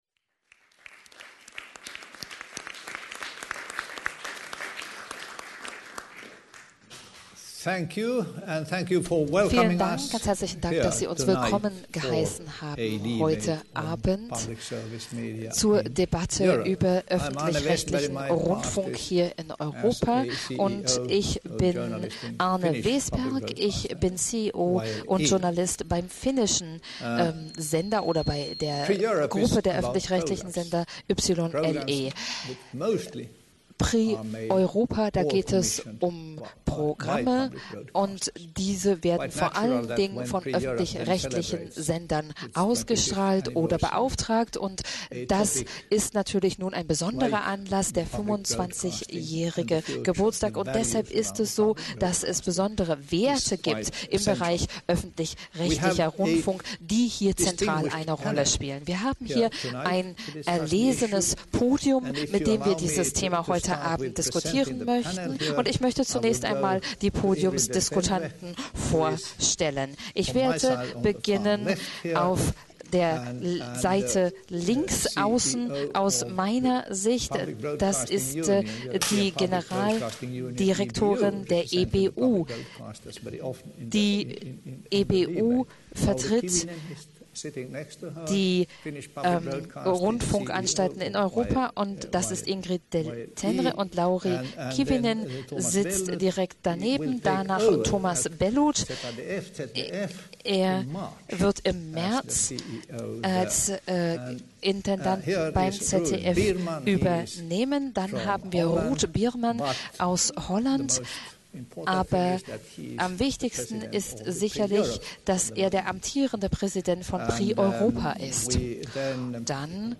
No audio playback capabilities, please download the audio belowDownload Audio: Closed Format: MP3 Download Zweikanalton: links Originalsprache – rechts deutsche Übersetzung
No audio playback capabilities, please download the audio below Download Audio: Closed Format: MP3 Download Zweikanalton: links Originalsprache – rechts deutsche Übersetzung Was: EUROPEAN LEADERSHIP PANEL Values and more. On the future of Public Service Media in Europe. Wo: Berlin, Collegium Hungaricum Wann: 27.10.2011, 19:30 Uhr Wer: (v.l.n.r.)